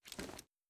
Index of /fastdl/sound/weapons/ak103
slideback.wav